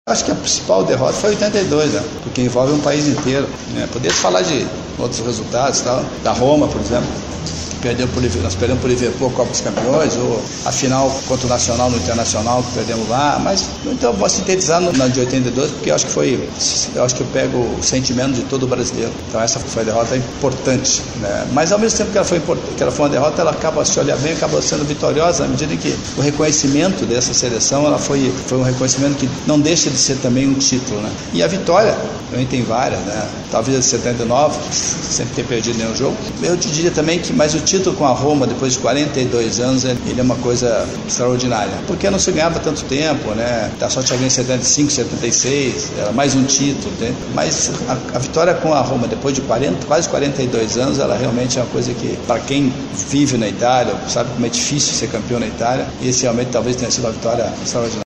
Antes da palestra, Falcão participou de uma conversa com jornalistas. Questionado sobre qual foi a maior derrota da vida no mundo esportivo, ele relembrou a Copa do Mundo de 1982, quando o Brasil foi eliminado na segunda fase da competição.